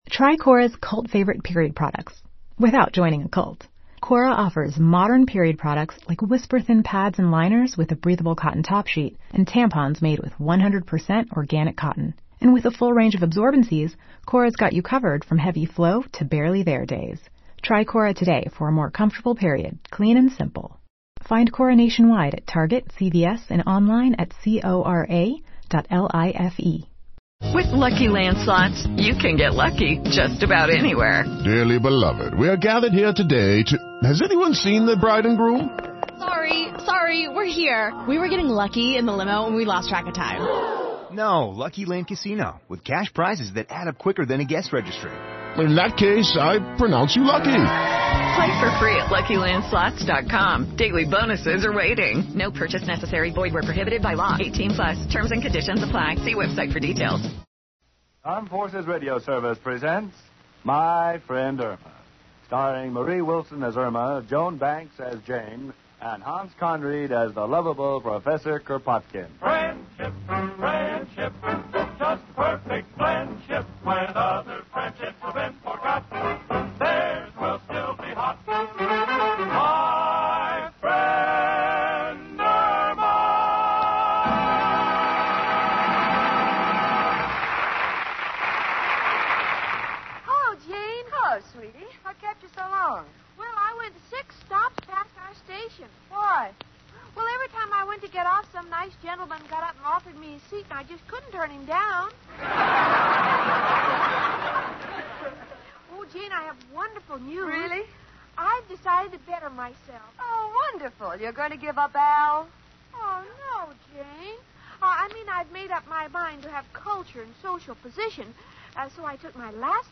"My Friend Irma," the classic radio sitcom that had audiences cackling from 1946 to 1952!
Irma, played to perfection by the inimitable Marie Wilson, was the quintessential "dumb blonde."